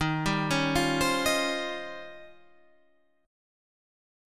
Eb13 chord